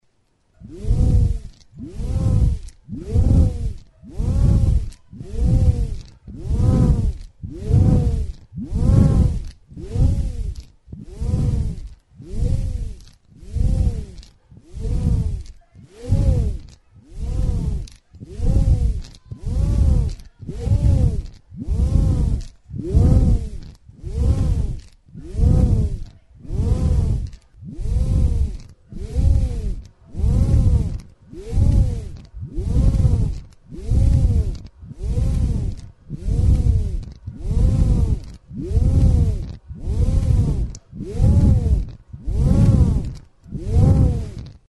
Aerophones -> Free-vibrating
Recorded with this music instrument.
Lau zuloko plastikozko botoi beltza da, zuloetatik pasatzen den sokatxo batekin.